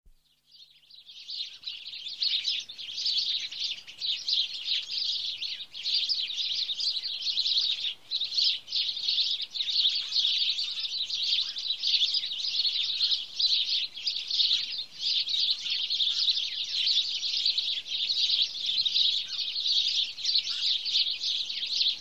Звуки чириканья воробья
Звонкий трель воробья